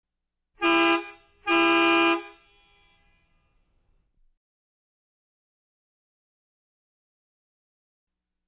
Звуки тягача
Звук сигнала грузовика два гудка